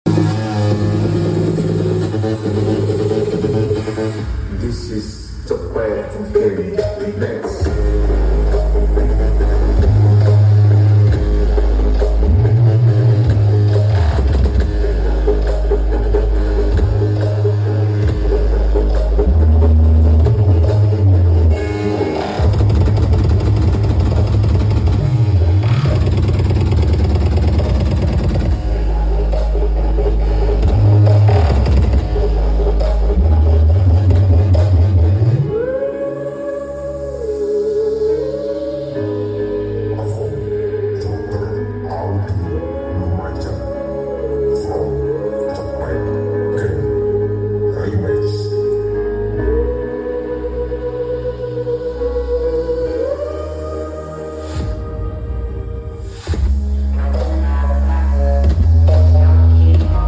Checksound pakai jingle sakralnya Joker sound effects free download